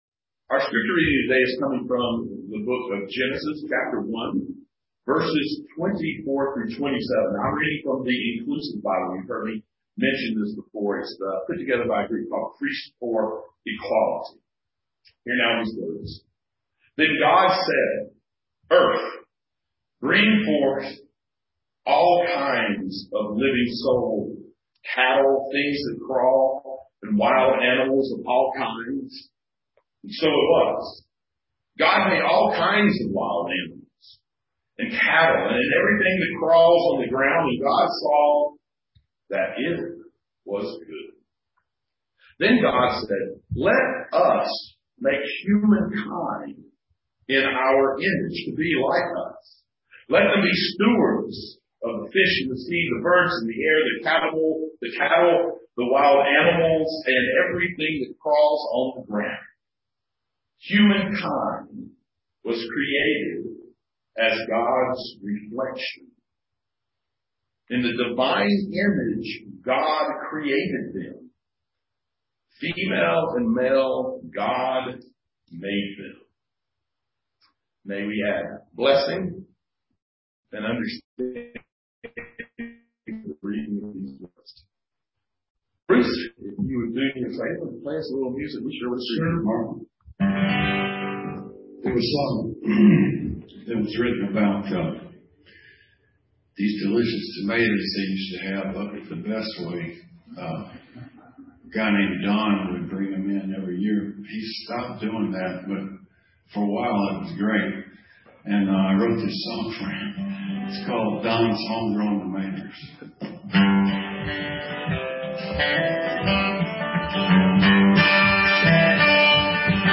(streamed on Facebook and Zoom)